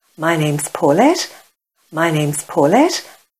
However, there is a very irritating chirpy sound which is apparent only in the vocal section of the audio. It sounds like some sort of distortion or sibilance and nothing I’ve tried on Audacity will eliminate it.
There are conspicuous artifacts on “s” & "f"sounds.